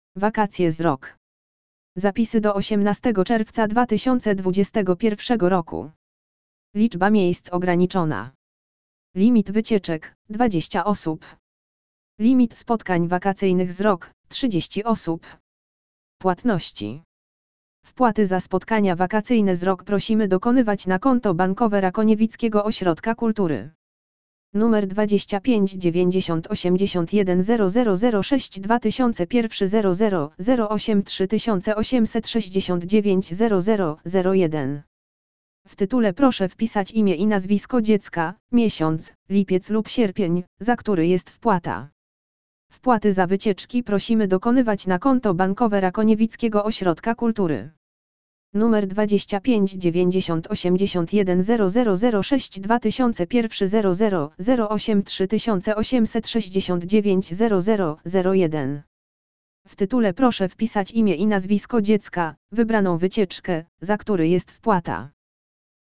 LEKTOR AUDIO WAKACJE Z RAKONIEWICKIM OŚRODKIEM KULTURY
lektor_audio_wakacje_z_rakoniewickim_osrodkiem_kultury.mp3